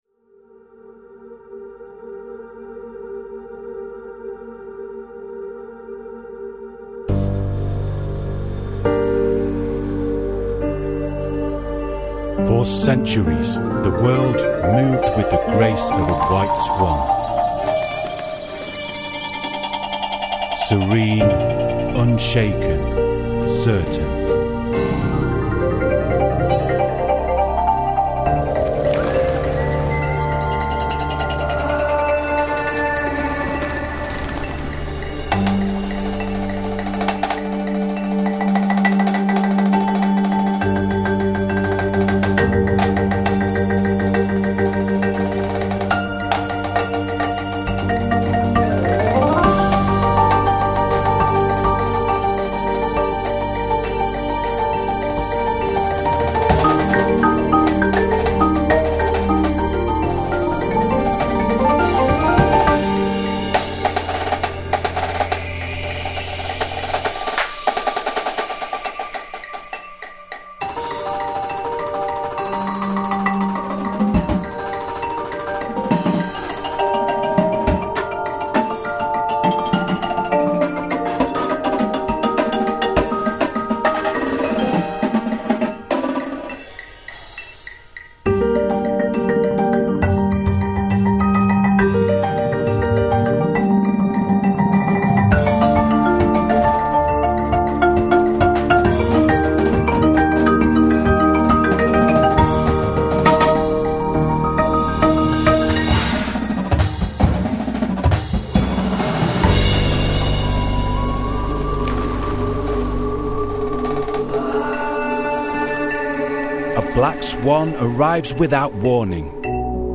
Indoor Percussion Shows
• Snares
• Tenors
• 5 Bass Drums
• Violin
• 6 Marimbas
• Xylo / Glock / Crotales
• 2 Synths
• Timpani
• Drumset